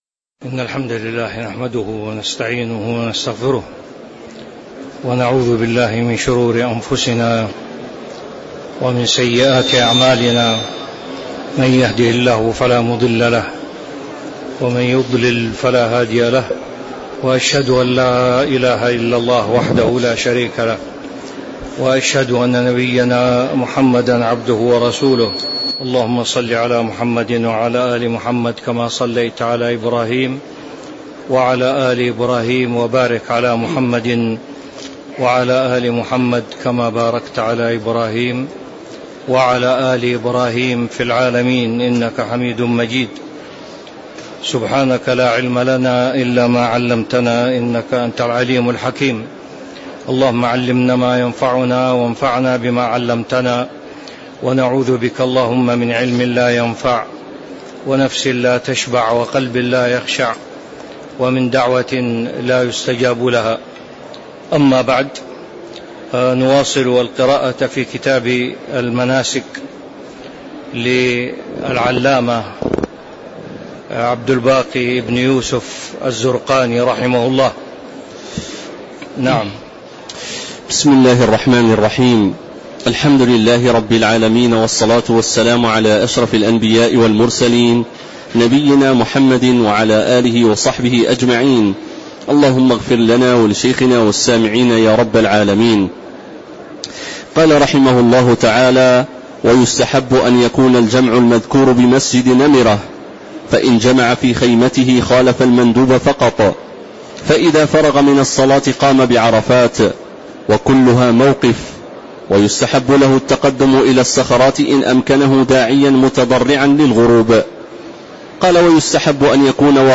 تاريخ النشر ٢١ ذو الحجة ١٤٤٦ هـ المكان: المسجد النبوي الشيخ